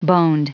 Prononciation du mot boned en anglais (fichier audio)
Prononciation du mot : boned